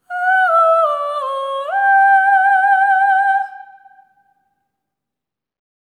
ETHEREAL09-R.wav